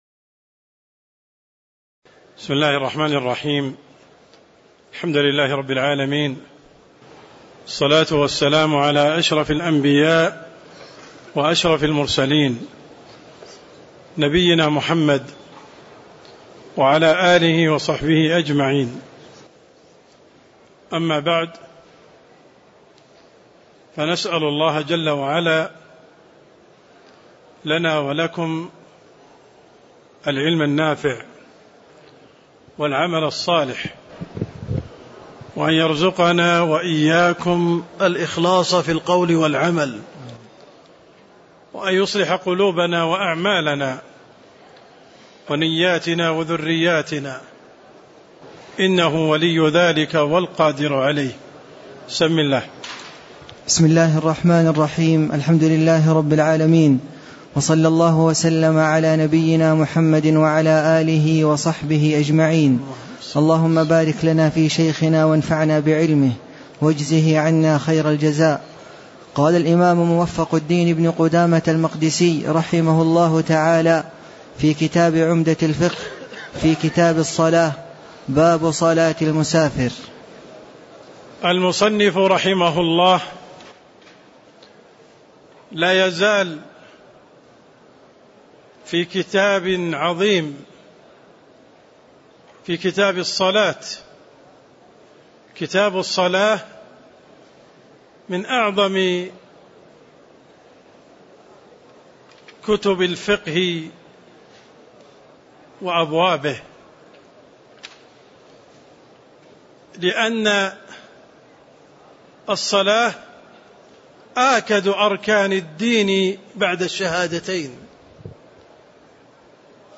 تاريخ النشر ٥ صفر ١٤٣٧ هـ المكان: المسجد النبوي الشيخ: عبدالرحمن السند عبدالرحمن السند باب صلاة المسافر (16)